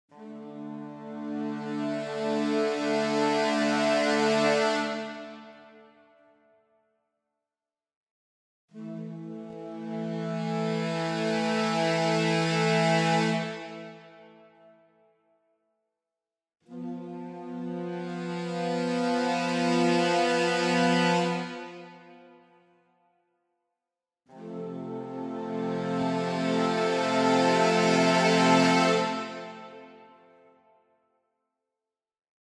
Hier die Saxofone, Crescendo-Artikulation, ohne Schnickschnack, also ohne Dynamikkontrolle und auch ohne Varispeed: